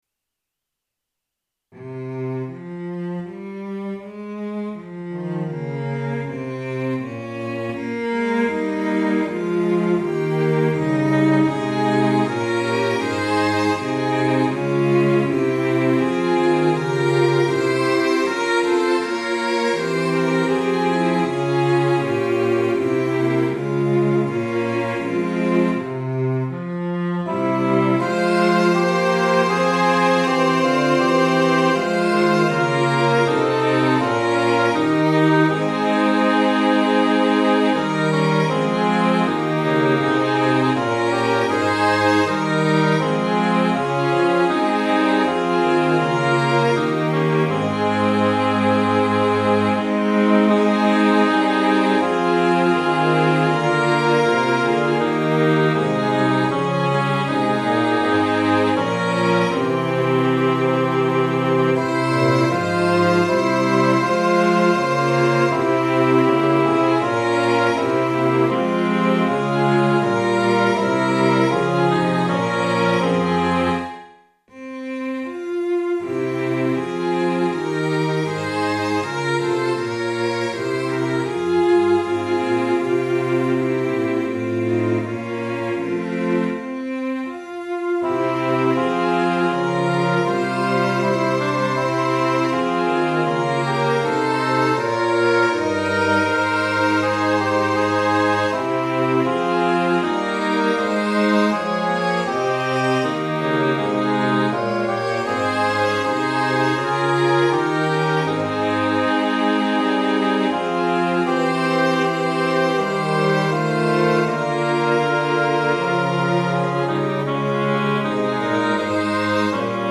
Fl Ob Cl Fg Str